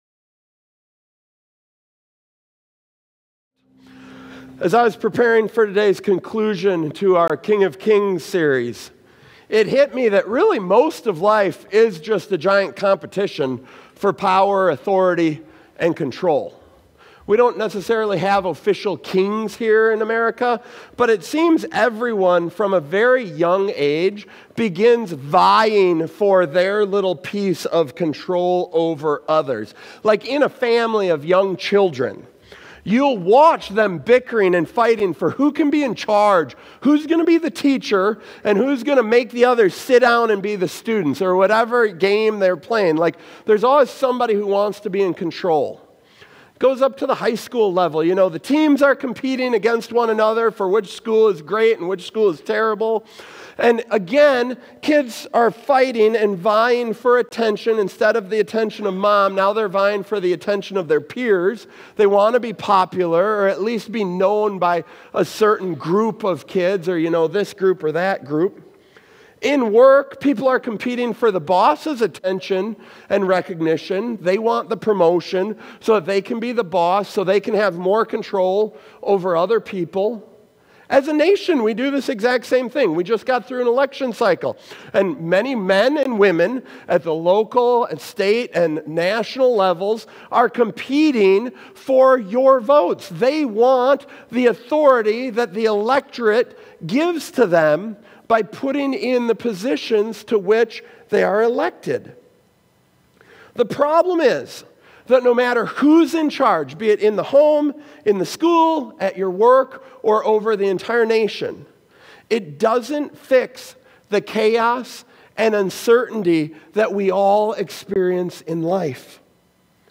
Worship Crowned Watch Listen Read Save This sermon concludes the “King of Kings” series by focusing on Jesus Christ as the ultimate King who fulfills scripture’s promises and brings hope amid life’s chaos.